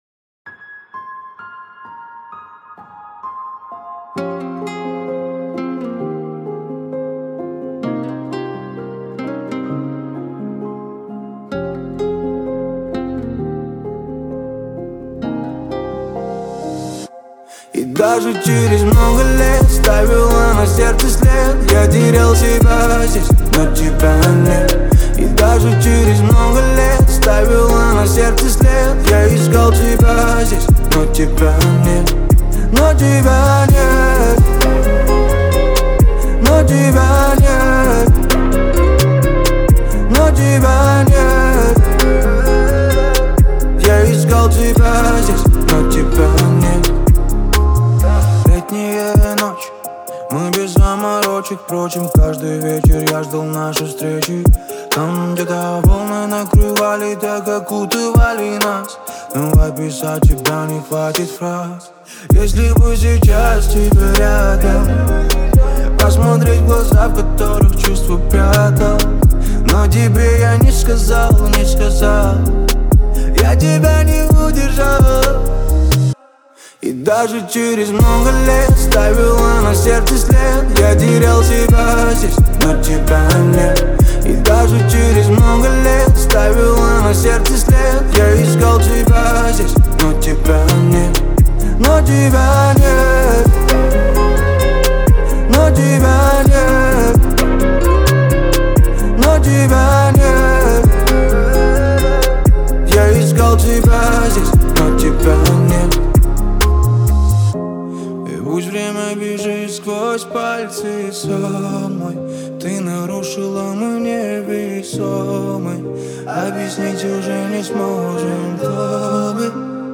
это трогательный поп-балладный трек
выразительное вокальное исполнение и атмосферное звучание